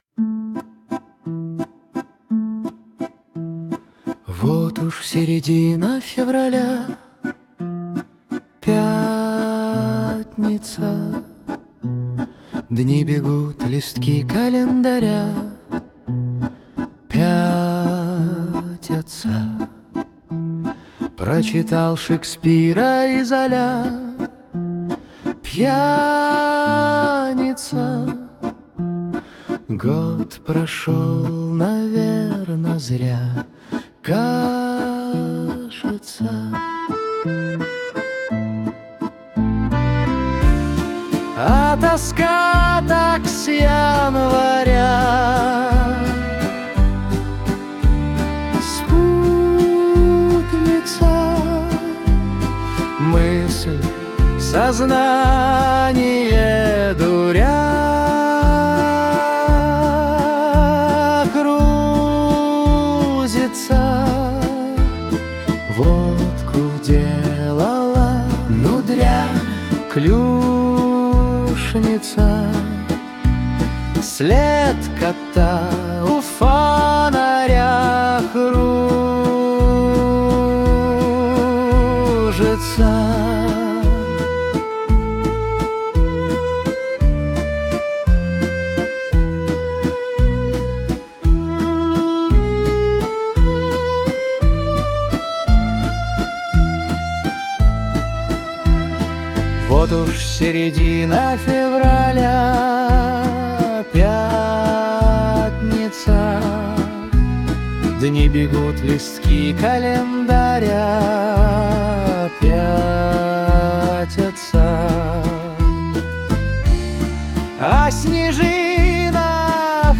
• Аранжировка: Ai
• Жанр: Шансон